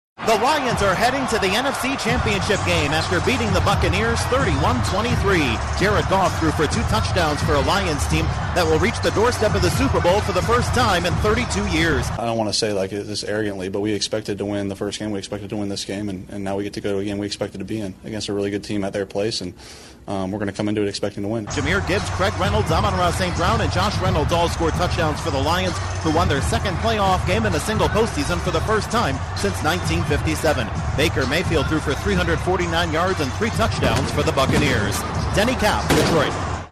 The Lions are heading to San Francisco after hanging on to beat the Buccaneers inside a rowdy Ford Field. Correspondent